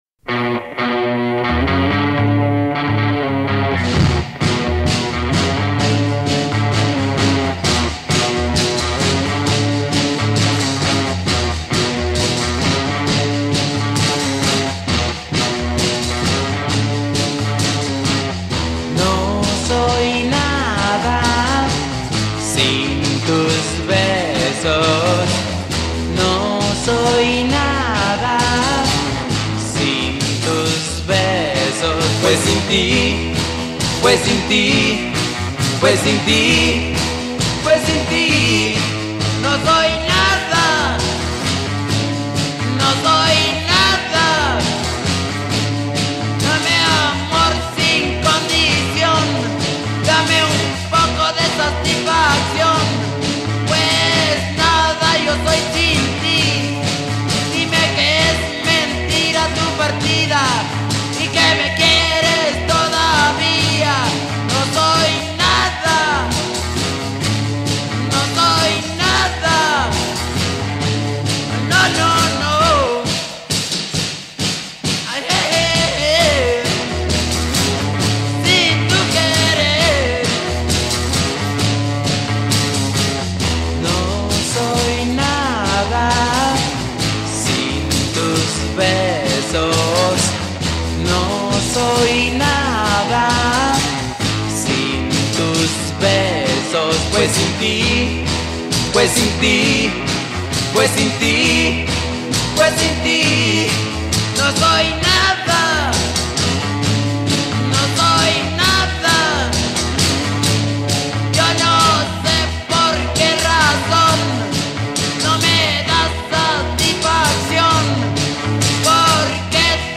Spanish cover
Mexican band